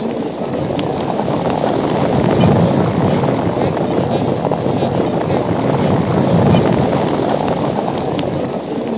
AN  ANTELOPE.wav